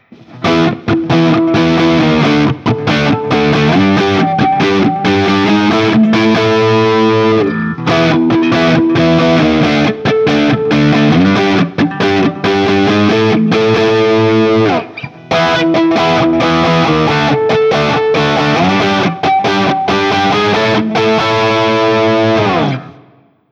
A Barre Chords 2
As usual, for these recordings I used my normal Axe-FX II XL+ setup through the QSC K12 speaker recorded direct into my Macbook Pro using Audacity. I recorded using the ODS100 Clean patch, as well as the JCM-800 and one through a setting called Citrus which is a high-gain Orange amp simulation.
For each recording I cycle through the neck pickup, both pickups, and finally the bridge pickup. All knobs on the guitar are on 10 at all times.